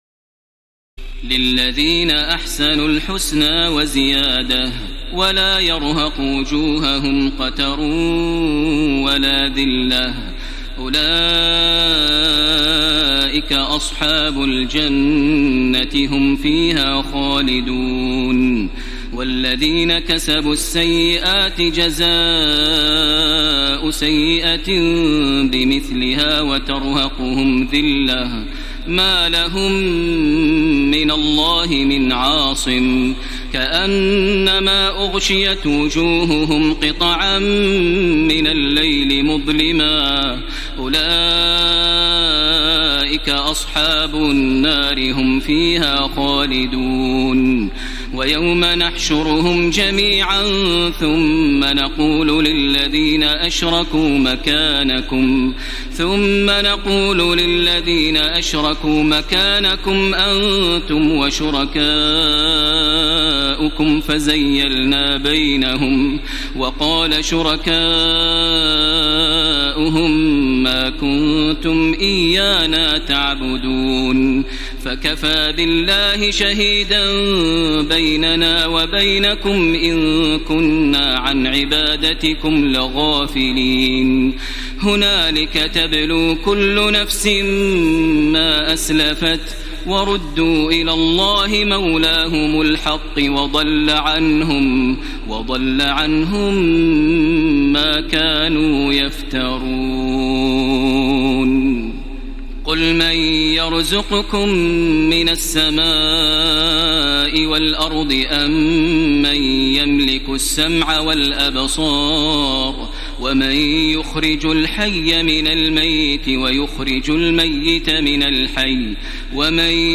تراويح الليلة العاشرة رمضان 1434هـ من سورة يونس (26-109) Taraweeh 10 st night Ramadan 1434H from Surah Yunus > تراويح الحرم المكي عام 1434 🕋 > التراويح - تلاوات الحرمين